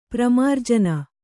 ♪ pramārjana